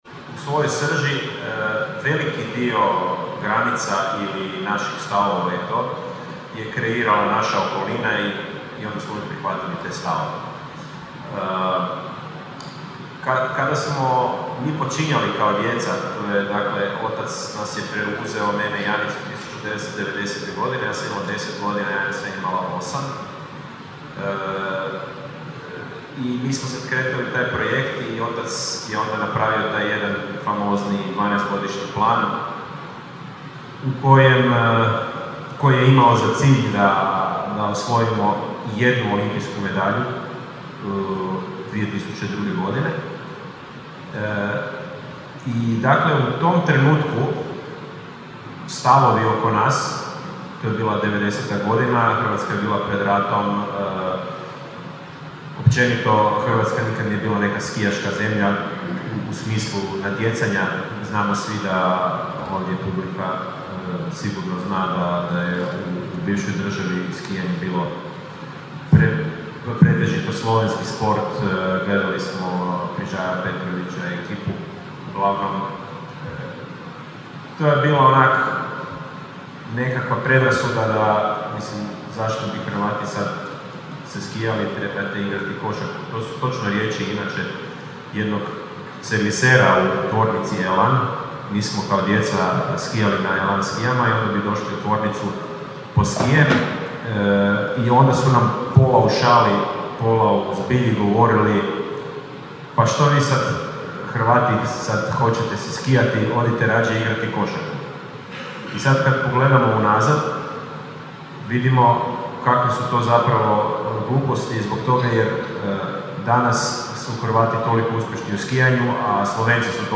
Grad Labin održao je predblagdansko druženje Snaga zajedništva – Labin pred blagdane uz gospodarstvenike, kulturnjake, sportaše i civilno društvo.
Gost druženja bio je Ivica Kostelić, jedan od najuspješnijih hrvatskih sportaša, koji je govorio na temu „Granice su u glavi – što nas pokreće da idemo dalje“.